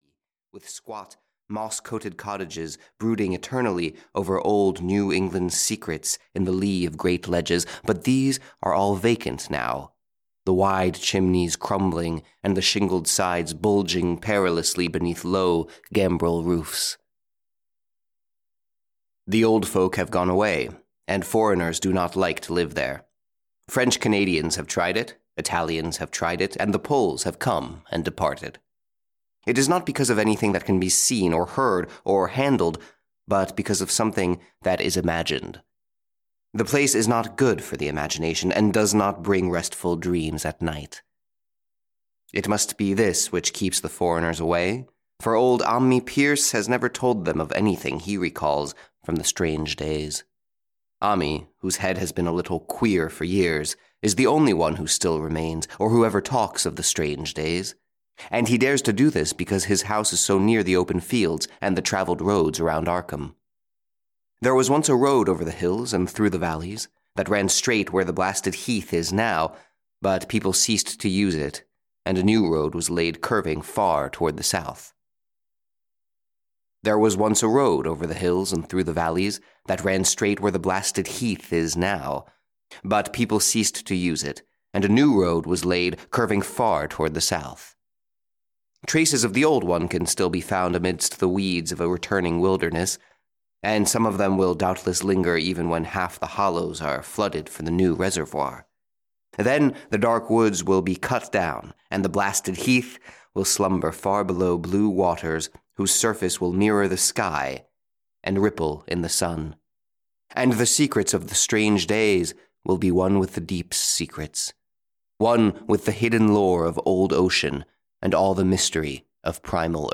Audio kniha22 Top Stories of H. P. Lovecraft & H. G. Wells (EN)
Ukázka z knihy